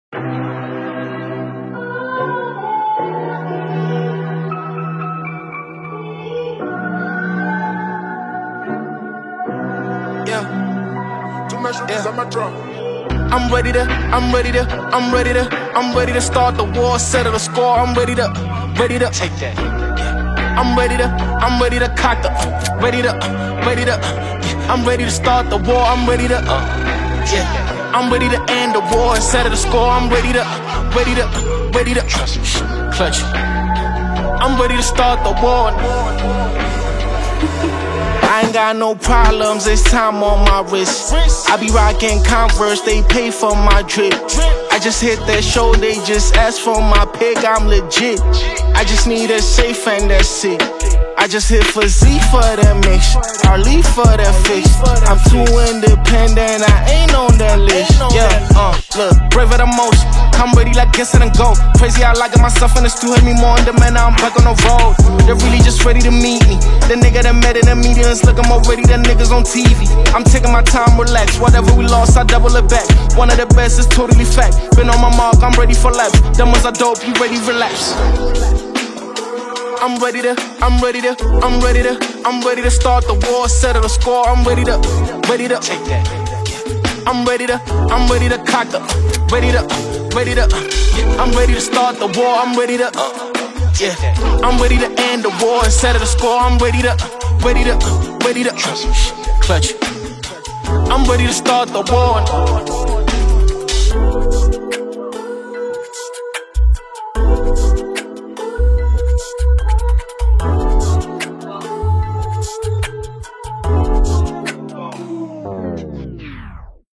a hard-hitting new track
modern South African hip-hop